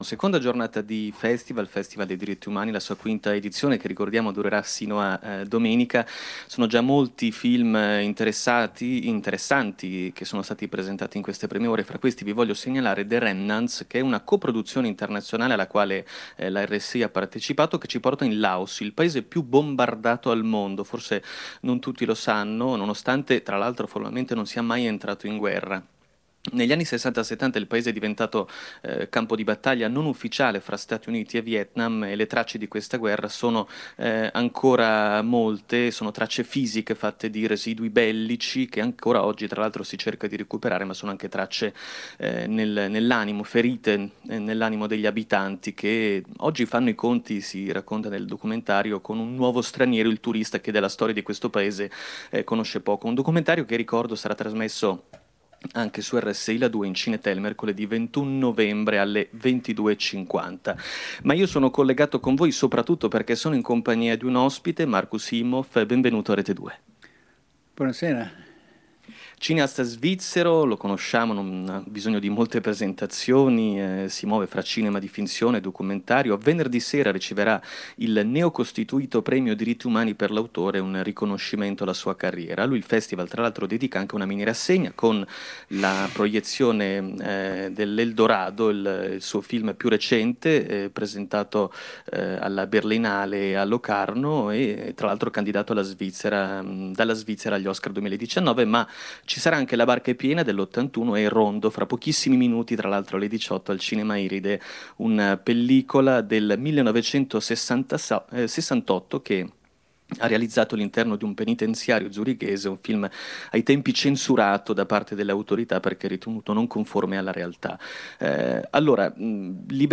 Film Festival Festival Diritti Umani. Intervista a Markus Imhoof